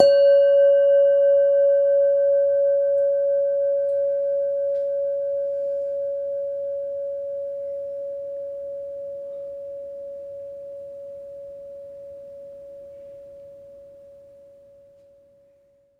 mono_bell_-1_C#_16sec
bell bells bell-set bell-tone bong ding dong ping sound effect free sound royalty free Sound Effects